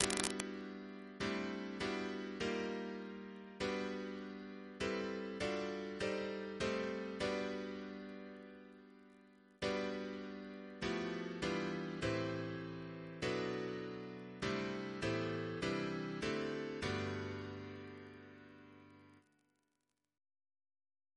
Double chant in G Composer: Daniel Pinkham (1923-2006), Organist of King's Chapel, Boston Reference psalters: ACP: 87; H1940: 792; H1982: S212